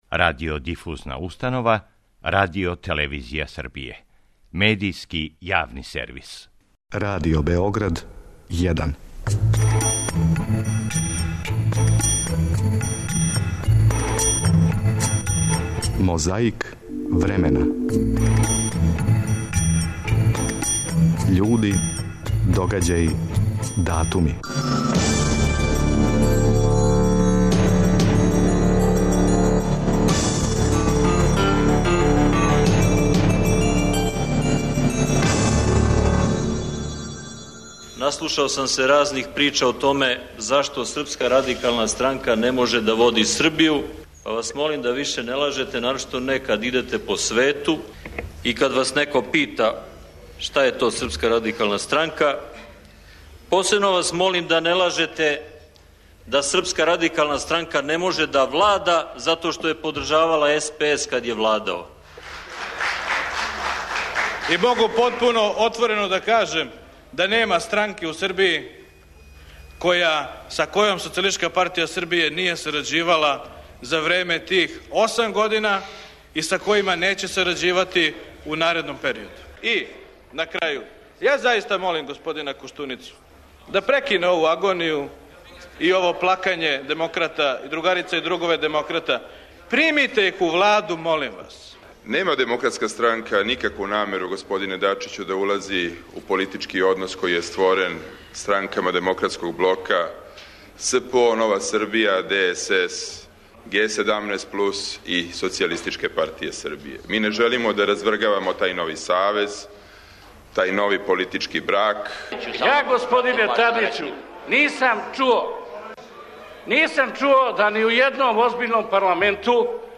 Како се диванило у Скупштини Србије што другог, што трећег марта 2004. године? Верујемо да ћете лако препознали Томислава Николића, Ивицу Дачића, Бориса Тадића и Велимира Илића.
Чућете и како је на другом светском сабору Српског покрета обнове, 6. марта 1993. године говорио Вук Драшковић.
3. марта 1975. године, на првој седници Савета федерације, говорио је друг Тито.
Подсећа на прошлост (културну, историјску, политичку, спортску и сваку другу) уз помоћ материјала из Тонског архива, Документације и библиотеке Радио Београда.